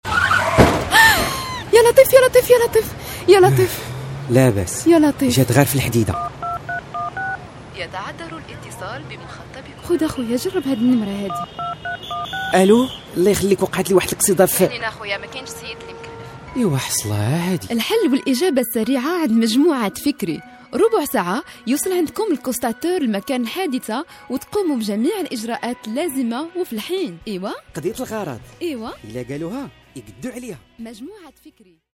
Votre voix-off arabe, promos, pub, documentaires...
Sprechprobe: Werbung (Muttersprache):